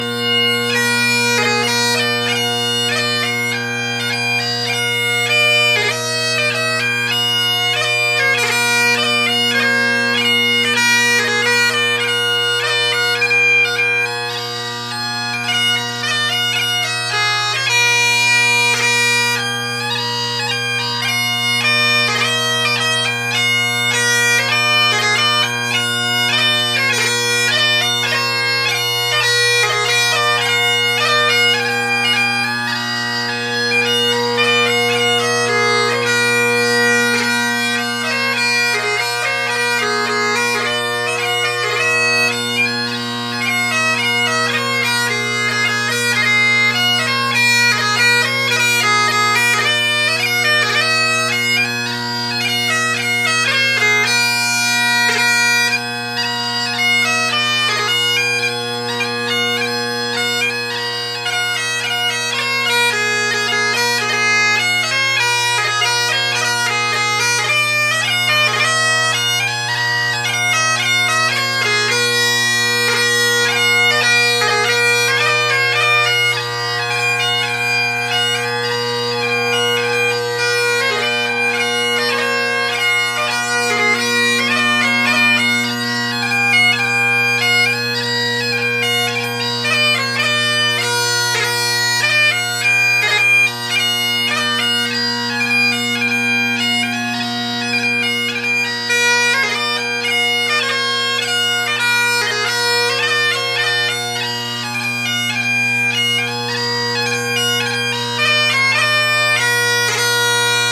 Great Highland Bagpipe Solo
Tuning, or the playing, isn’t great or perfect, but good enough for 20 minutes out of the shipping box!
Pipes with Ezee drone reeds: